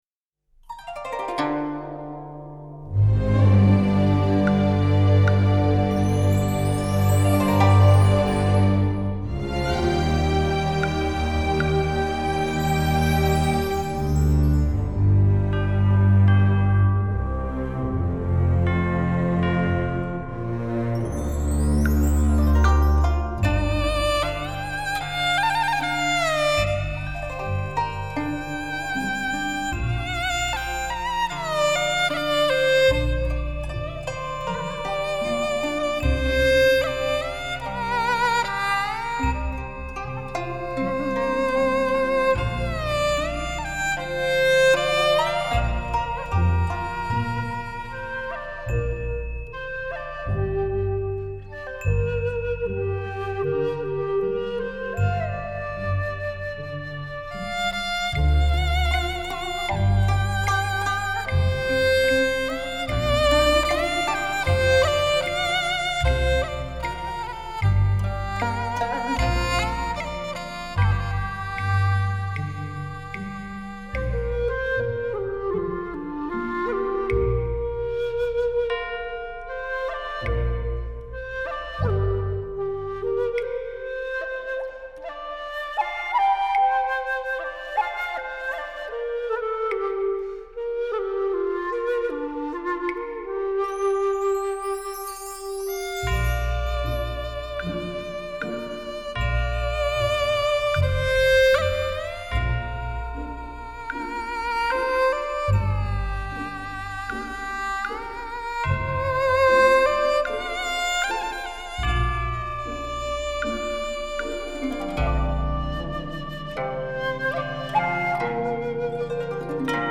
★十曲中國戲曲當中最經典的場面，巧妙的以西樂伴奏、中樂主奏，塑造出純中樂難以達到的沈渾豐厚、氣勢磅礡的絕佳音響效果。
豐厚華美的西式弦樂搭配氣韻靈動的中式樂器